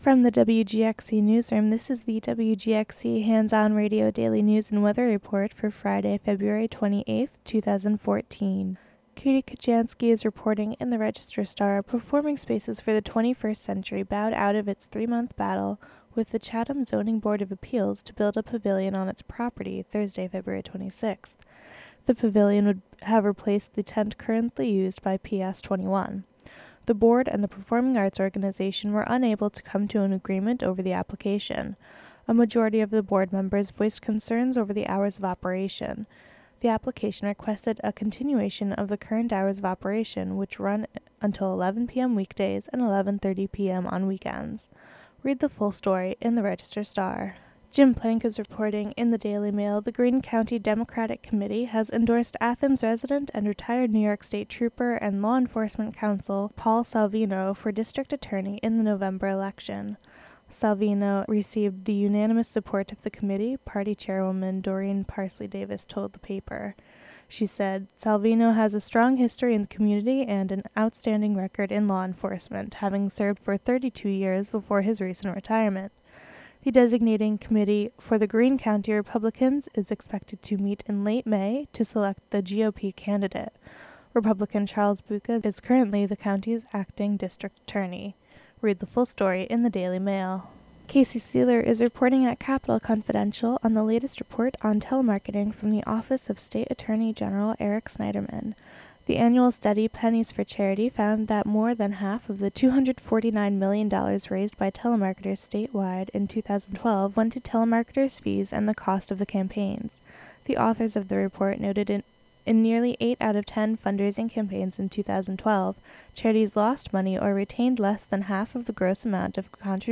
Local news and weather for Friday, February 28, 2014.